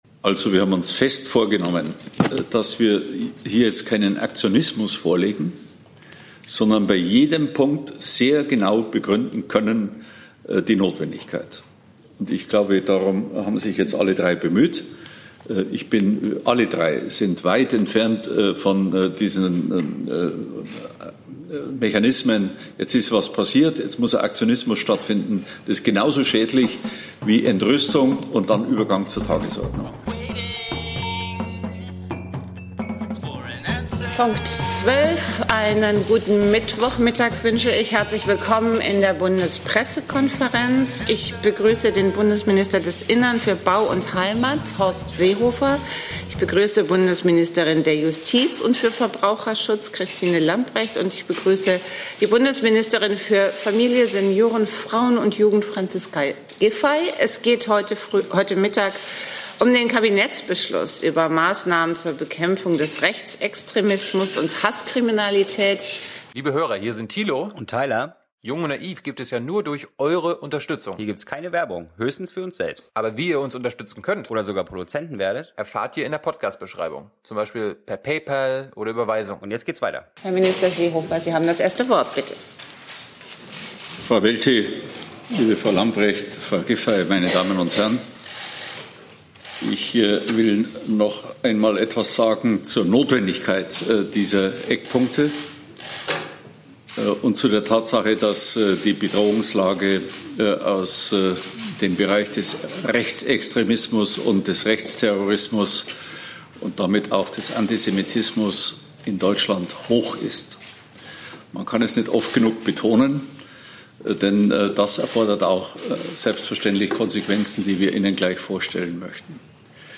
BPK - Maßnahmen der Bundesregierung gegen Rechtsextremismus & Hass im Netz ~ Neues aus der Bundespressekonferenz Podcast